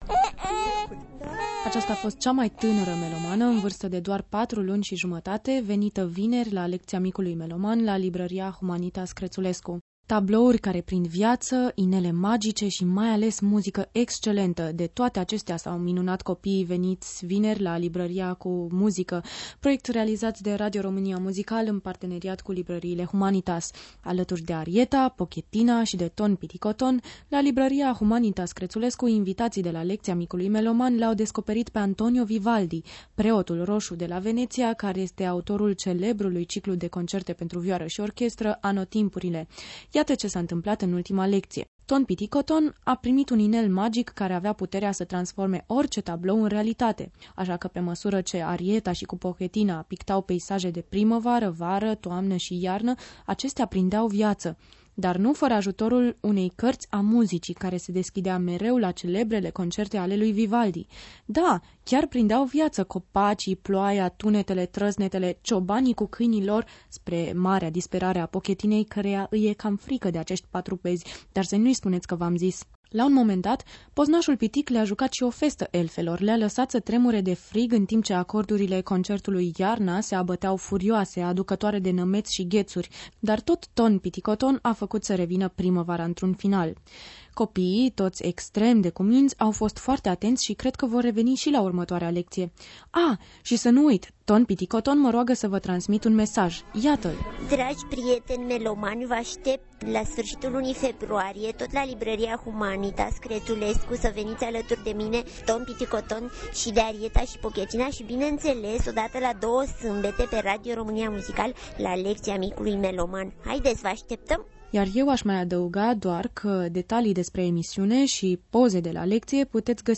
In ciuda vremii urate de afara, multi copii s-au strans la Libraria Humanitas Kretzulescu, cea care gazduieste in fiecare ultima zi de vineri a fiecarei luni acest proiect.